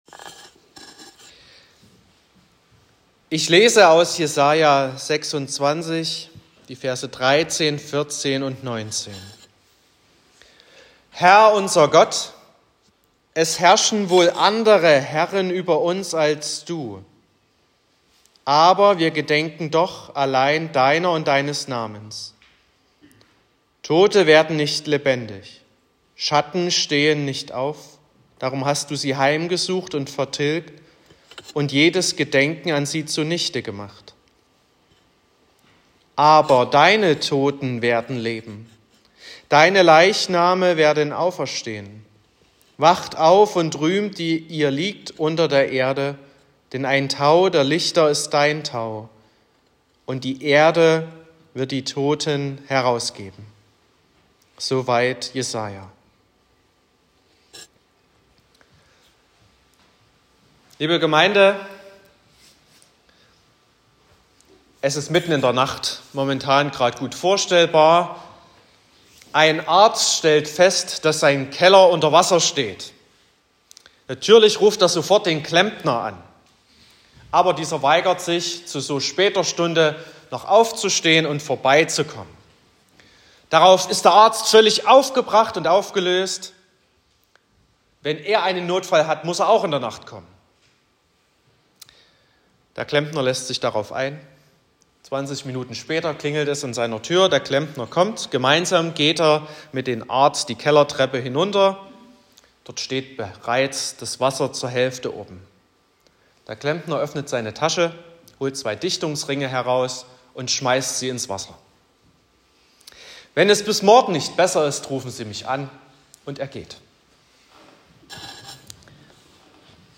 09.04.2023 – Osternachtsfeier, anschließend gemeinsames Frühstück
Predigt und Aufzeichnungen